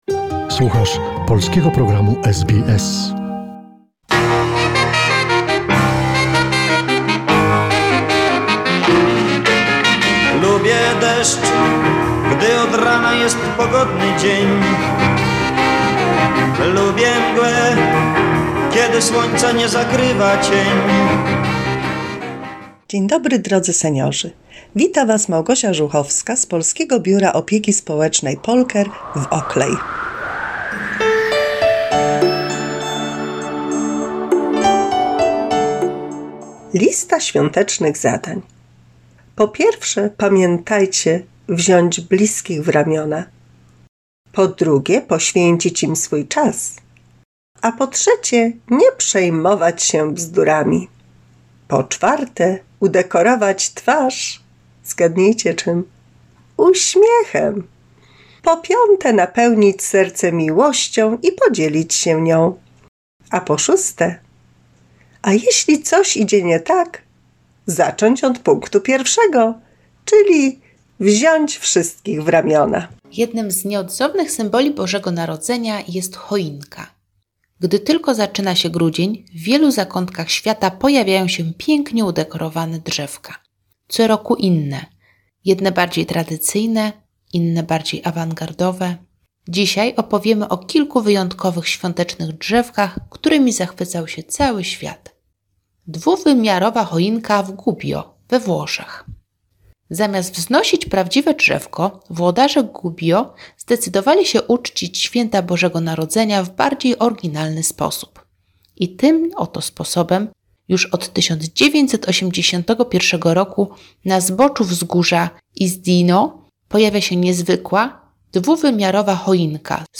88 mini radio drama for Polish seniors